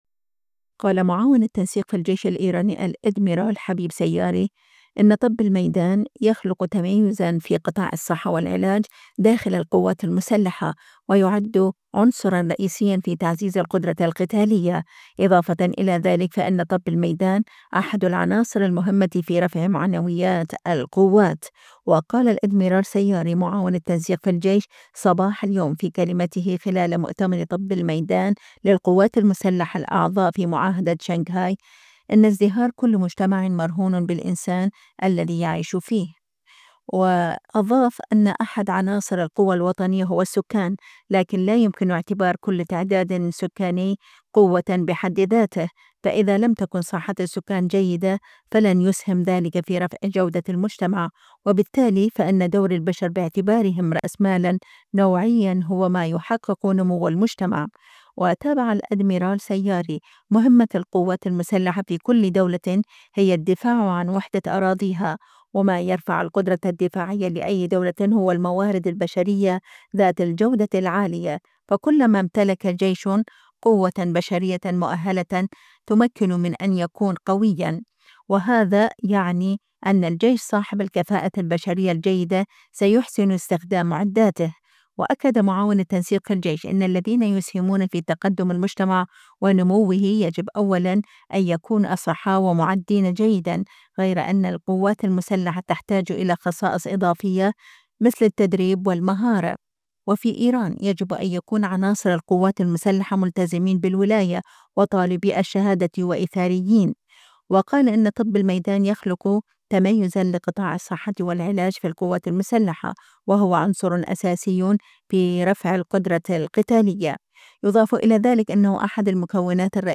وقال الأدميرال سياري، معاون التنسيق في الجيش، صباح اليوم في كلمته خلال مؤتمر طبّ الميدان للقوات المسلحة الأعضاء في معاهدة شنغهاي: إن ازدهار كل مجتمع مرهون بالإنسان الذي يعيش فيه.